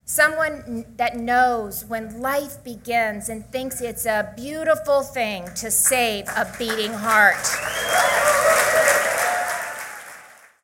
Reynolds joined DeSantis at a campaign rally last night in Des Moines.